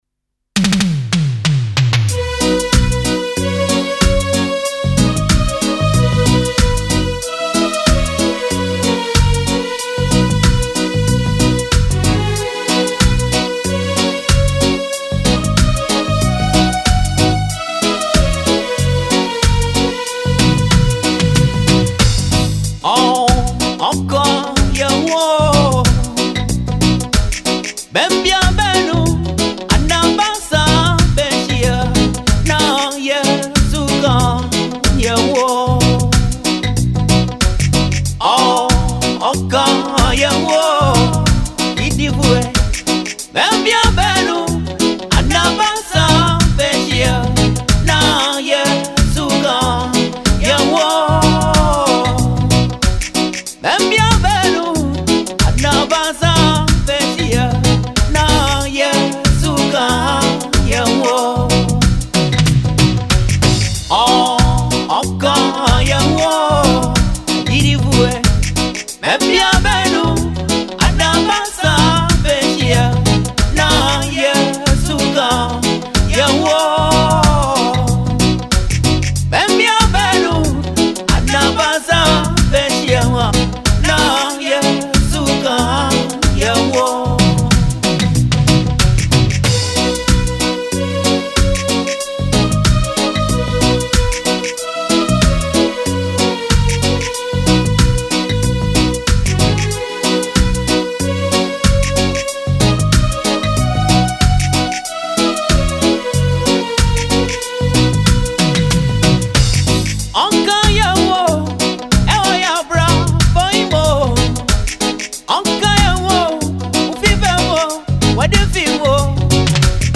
Highlife/Gospel song